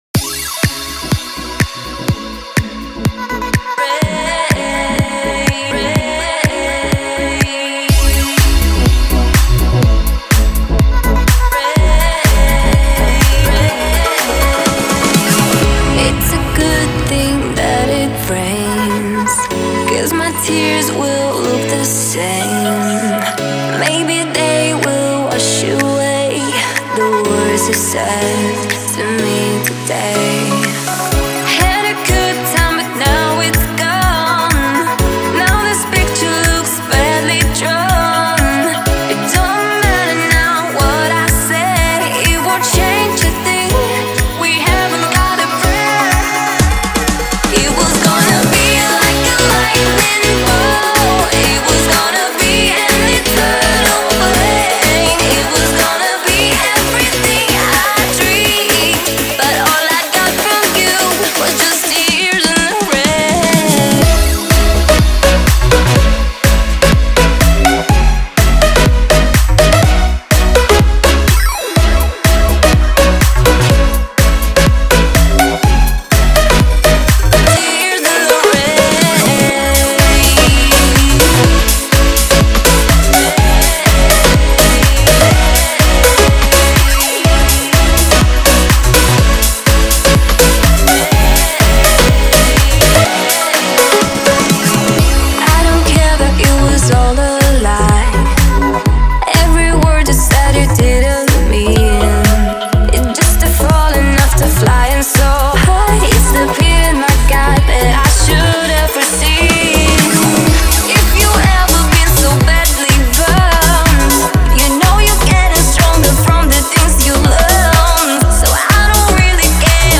Style: Future House, Dance, Up-tempo